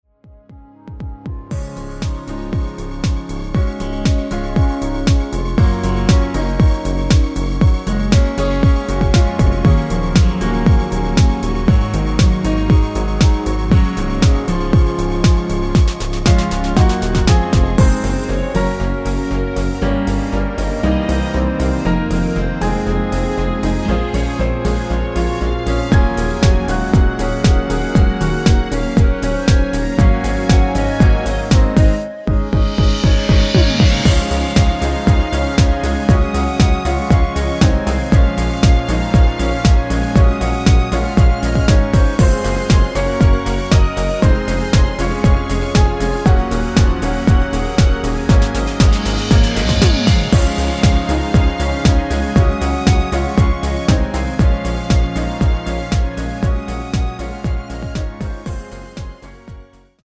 전주가 없는 곡이라 2마디 전주 만들어 놓았습니다.
Db
◈ 곡명 옆 (-1)은 반음 내림, (+1)은 반음 올림 입니다.
앞부분30초, 뒷부분30초씩 편집해서 올려 드리고 있습니다.